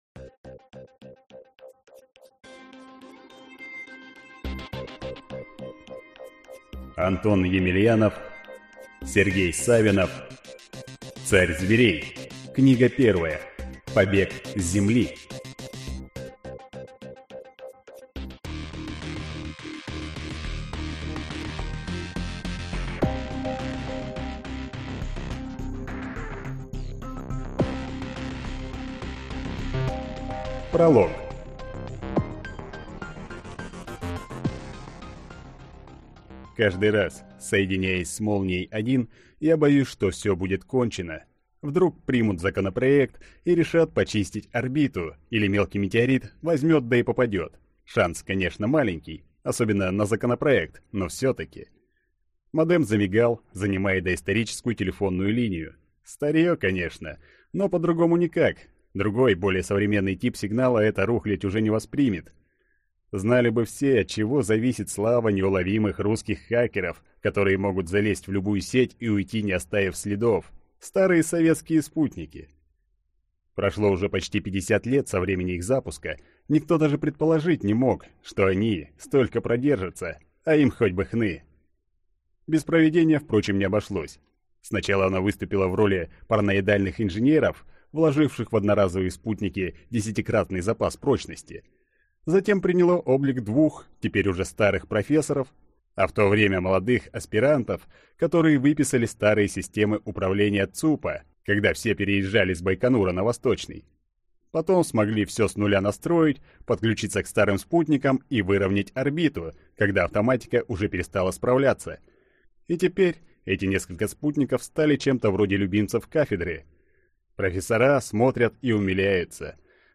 Аудиокнига Царь зверей.